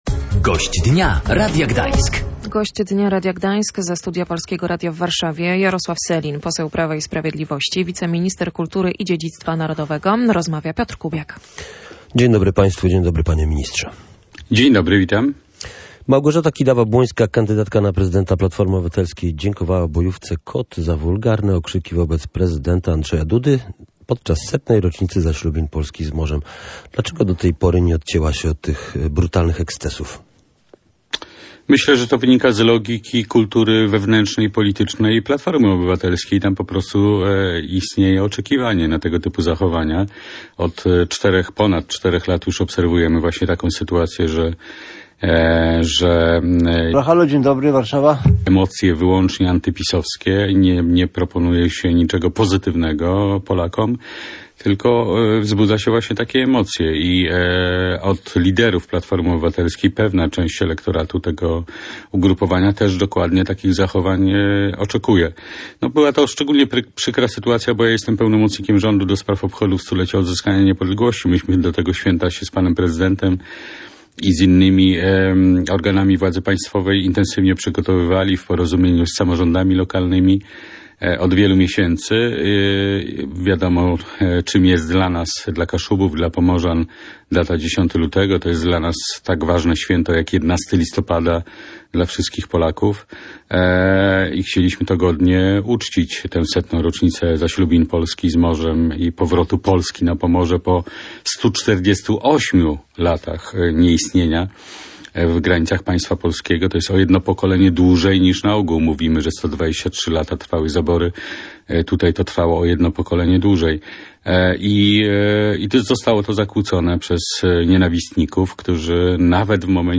Z wiceministrem Jarosławem Sellinem w porannym programie Radia Gdańsk